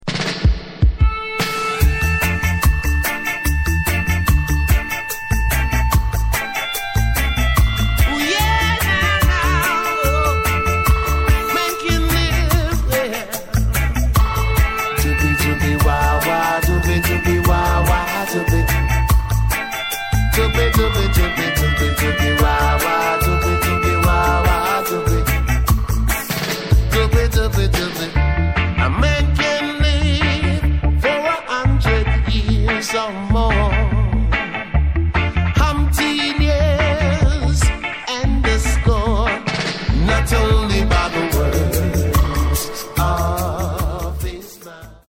Saxophone Version